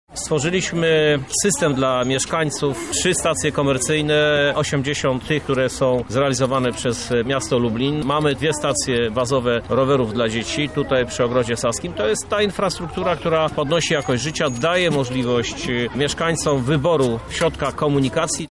O tegorocznych zmianach mówi Krzysztof Żuk, prezydent Miasta Lublin.